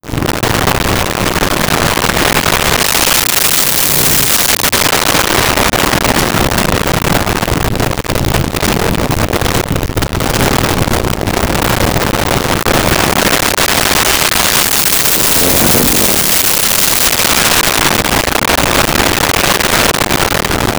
Motorcycle Fast Bys
Motorcycle Fast Bys.wav